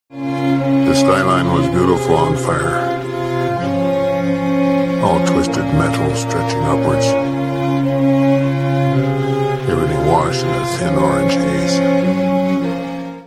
A clip from a film becomes the vocal part here